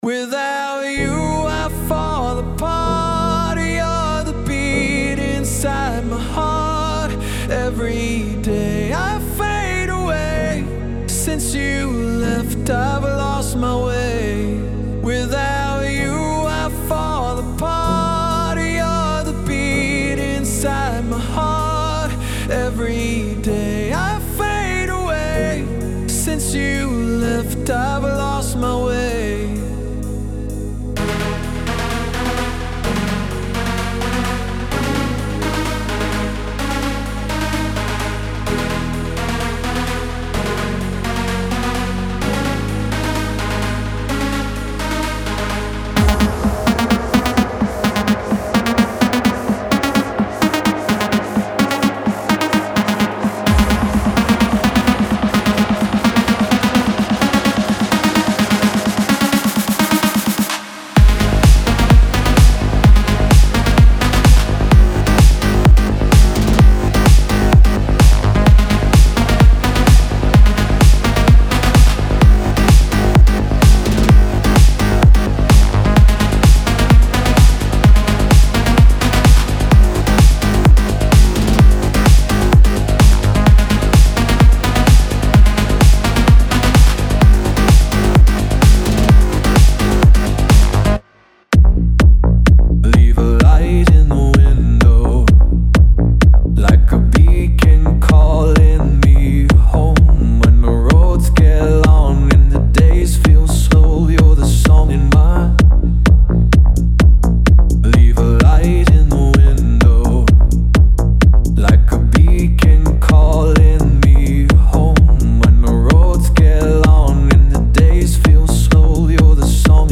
Melodic Techno Progressive House Techno
• 67 Loop Files including Drums, Synths, Bass & Leads
• 128-132 Bpm